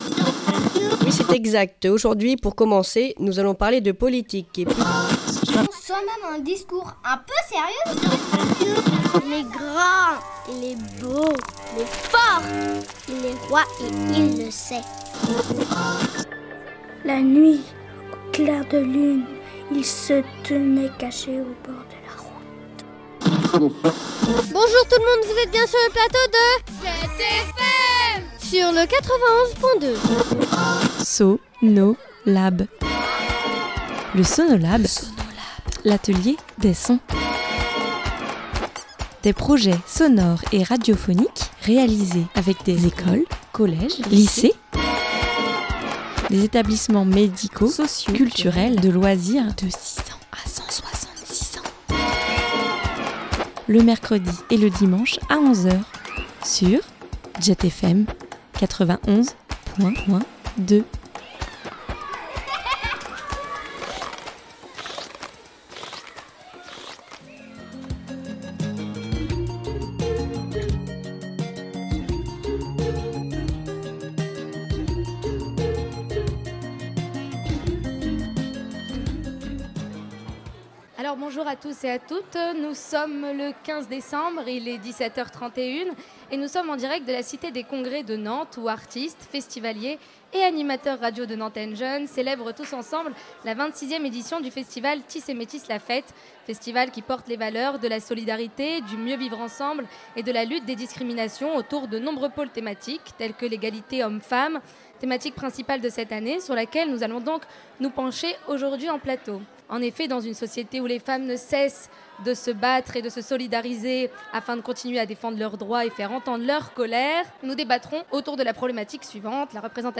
Voici la première émission réunissant 2 des 4 plateaux mis en place par Jet FM et animés par Nantenne Jeunes lors de la fête de « Tissé Métisse » qui a eu lieu le 15 décembre dernier à la Cité des Congrès de Nantes.
La deuxième partie de ce plateau sera une interview de Gérard Noiriel, interview réalisée par des membres de Nantenne Jeunes pendant le festival. Gérard Noiriel va nous parler de l’engagement mais aussi du phénomène des gilets jaune.